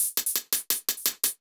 Index of /musicradar/ultimate-hihat-samples/170bpm
UHH_ElectroHatC_170-02.wav